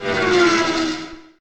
car_pass.ogg